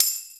tambourine2.wav